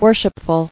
[wor·ship·ful]